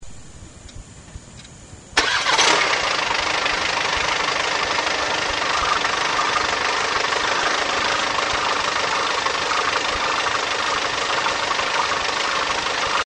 セルはキュンキュンキュンキュンと音がせず、いきなり
VW車はブンと掛かる。
セルモーターの音を録音するときは、SONYカセットコーダーTCM-59を
フロント右タイヤの右側に置いて録音する。
タイヤの前とか、バンパーの右に置くと、無駄な低音が強調される。